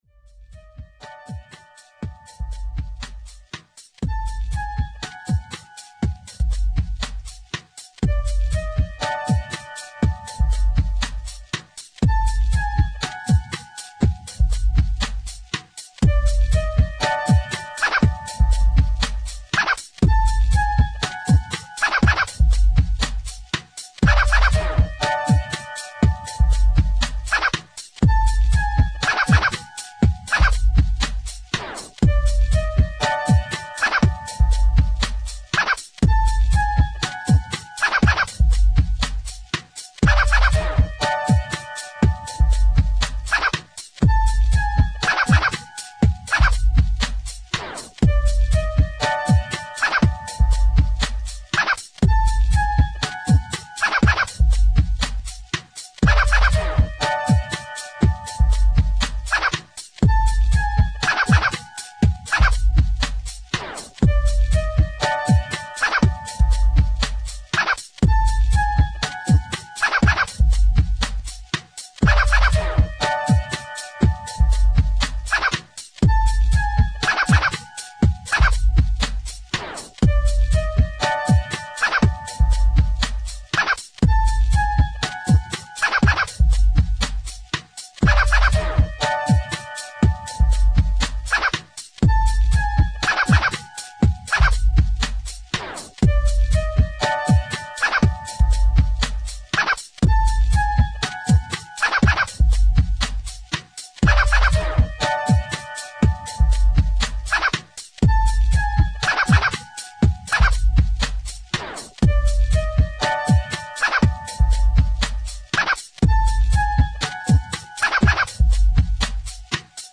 Mixed Songs
Nasty - Contains some of the strangest sound clips i found on our computer.